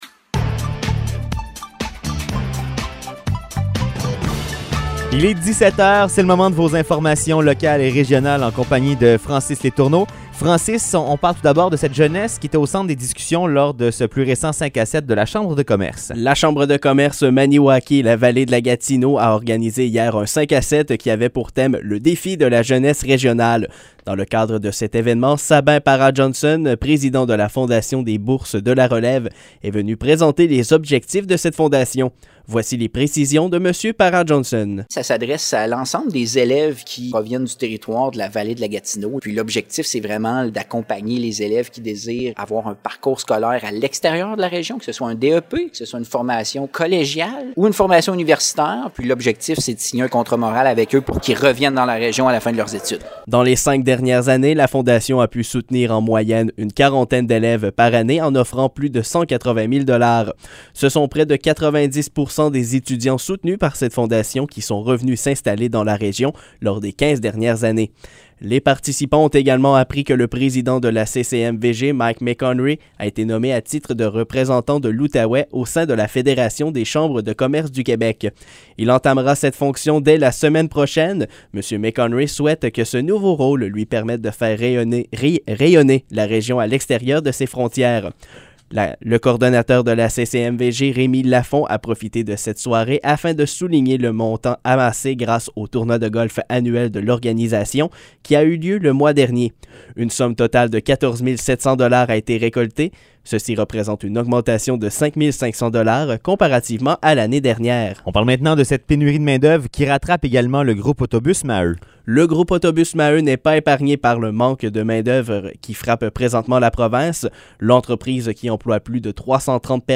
Nouvelles locales - 14 octobre 2021 - 17 h